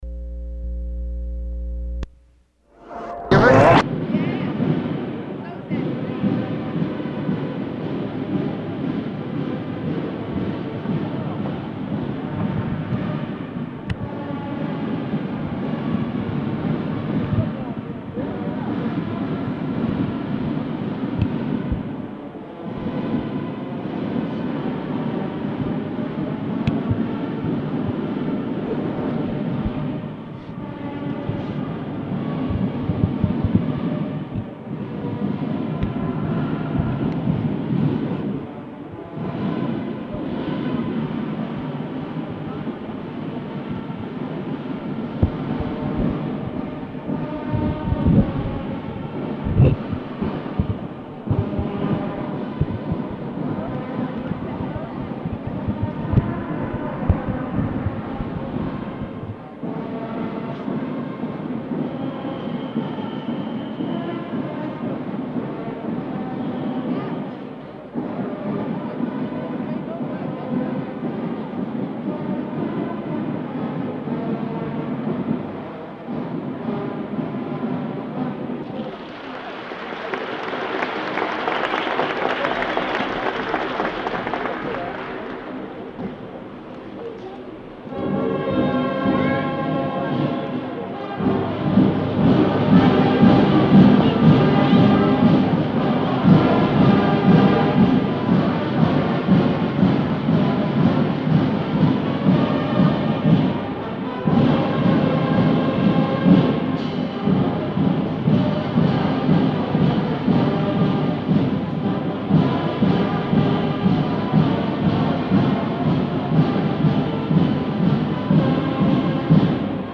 Scope & Content Audio recording containing the May 1966 dedication ceremony for the Exposition Center, located at 4145 East 21st Street, Tulsa, OK.
00:01 band playing 03:00 national Anthem, more band music 06:45 prayer
introductory speech of Oklahoma Governor Henry Bellmon
speech ends, horns begin